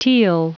Prononciation du mot teal en anglais (fichier audio)
Prononciation du mot : teal